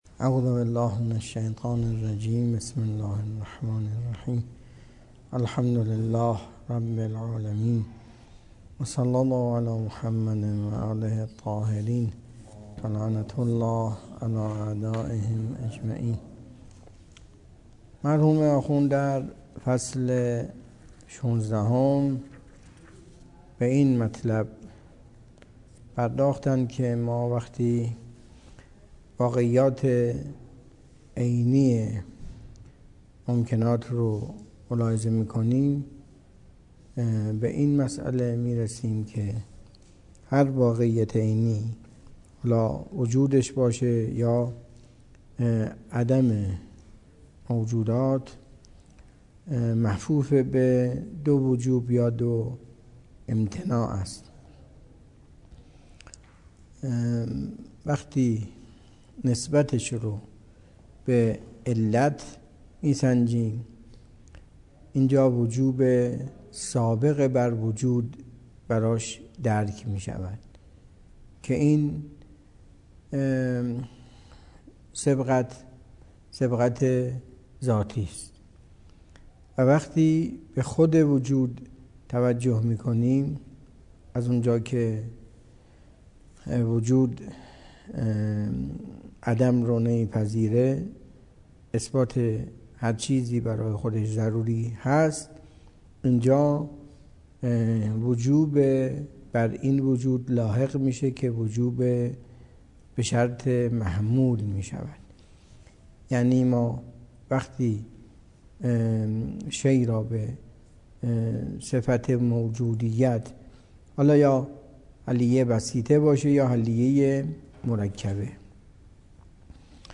درس فلسفه اسفار اربعه